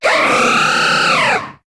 Cri de Xerneas dans Pokémon HOME.